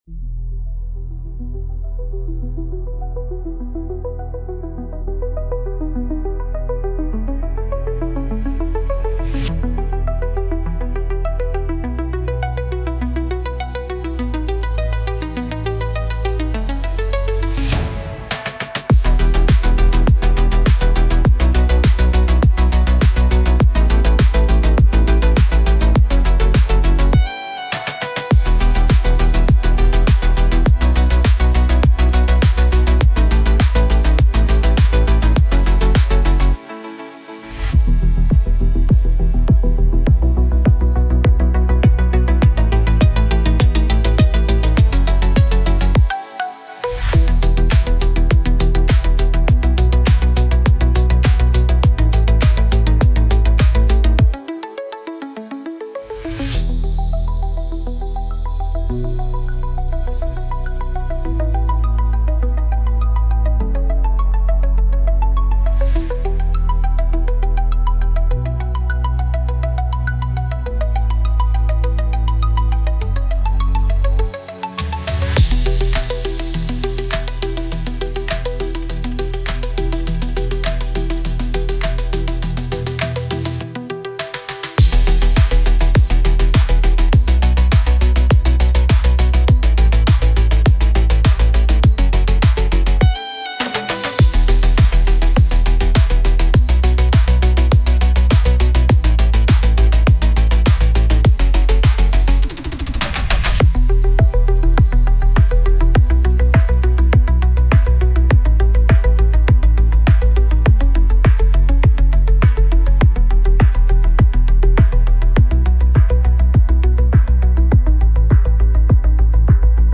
Instrumental - Real Liberty Media DOT xyz -- 4.00 mins